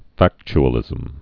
(făkch-ə-lĭzəm)